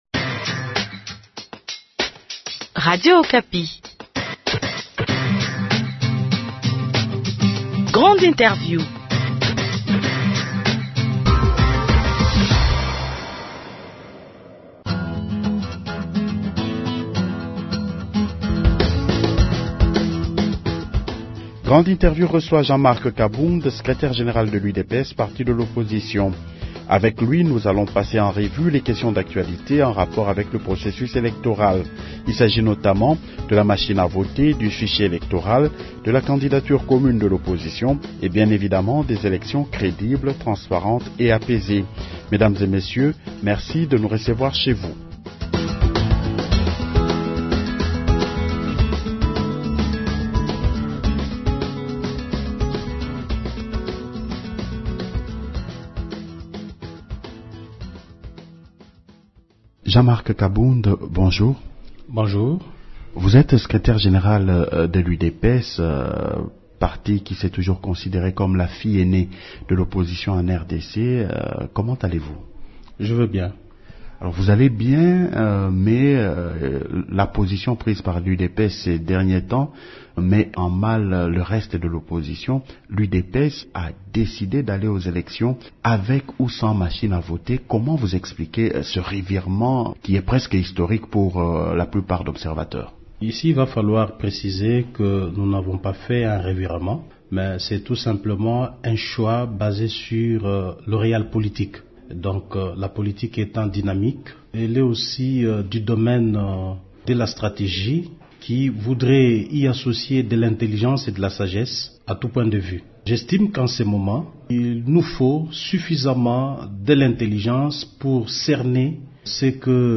Grande Interiew